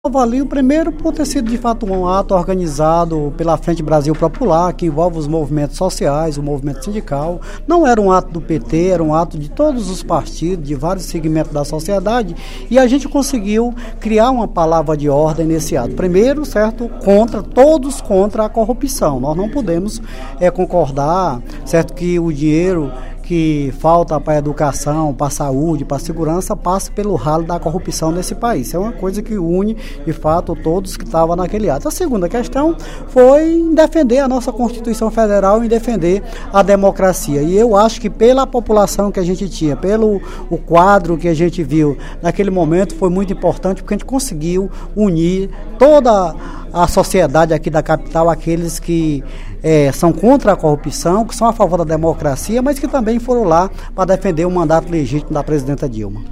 O deputado Moisés Braz (PT) destacou, em pronunciamento no primeiro expediente da sessão plenária da Assembleia Legislativa desta quarta-feira (23/03), a importância de ato realizado no último dia 18, pela Frente Brasil Popular, em todo o País.